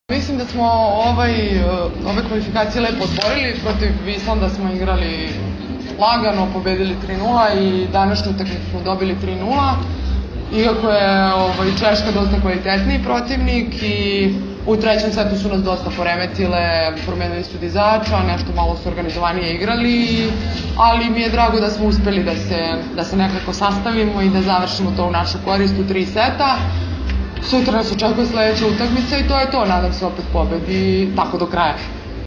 IZJAVA BOJANE ŽIVKOVIĆ